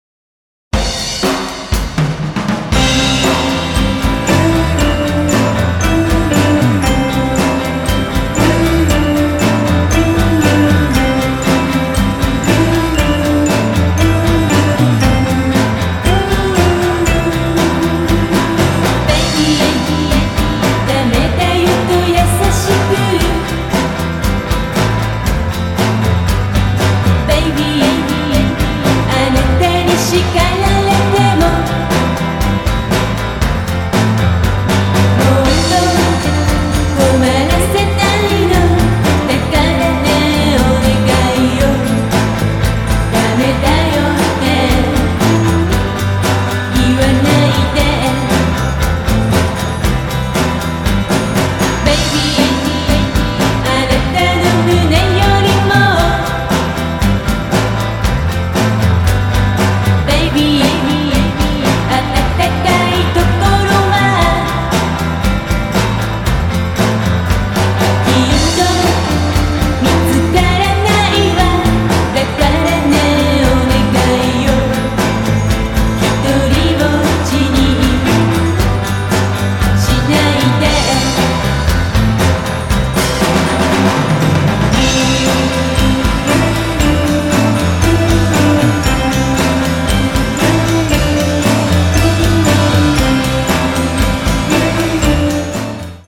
50年代～60年代のポップス/ロックンロールをガレージ感覚で聴かせてくれます！